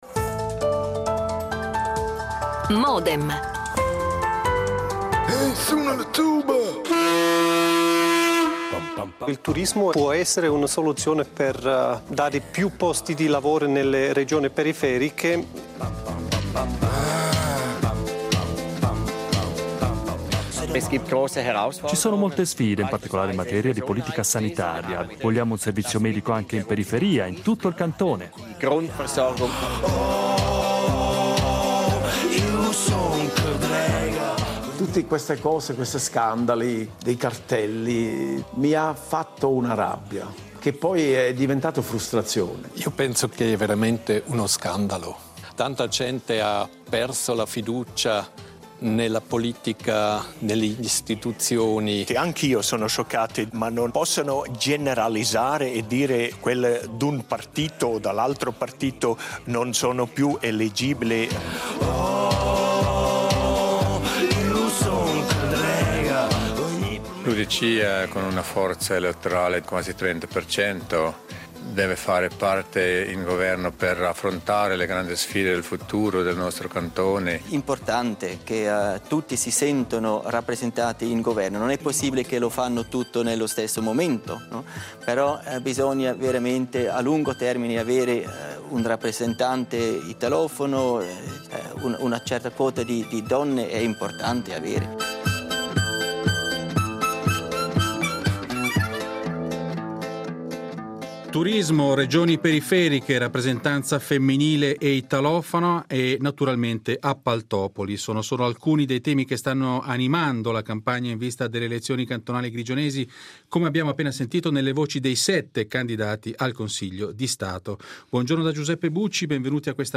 Di questo e altro parliamo con cinque candidati al Gran Consiglio, in rappresentanza dei cinque gruppi parlamentari presenti nel Legislativo:
L'attualità approfondita, in diretta, tutte le mattine, da lunedì a venerdì